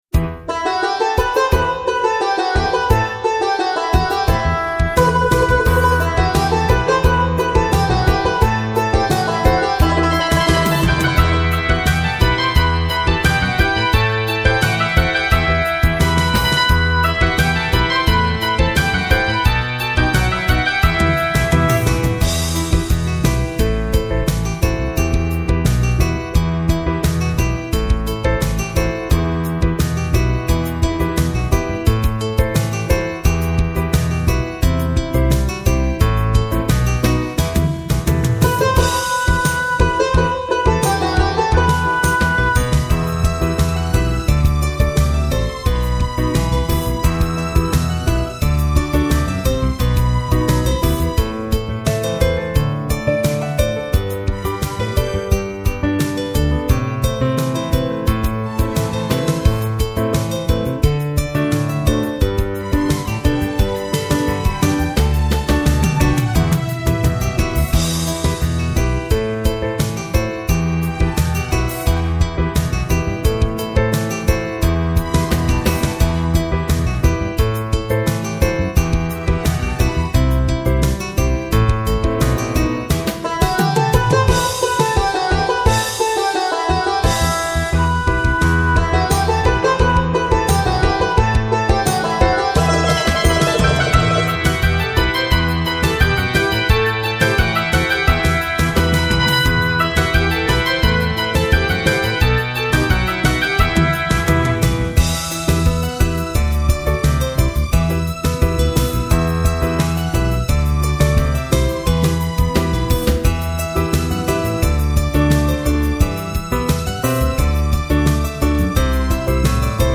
دانلود آهنگ بی کلام روز معلم